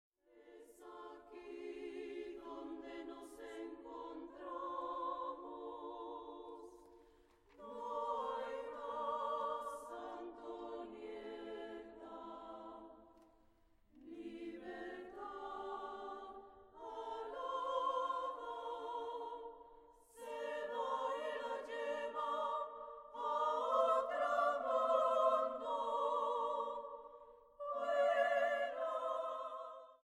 Coro femenino. 1:51
Grabado en: Teatro Aguascalientes, marzo, 2013.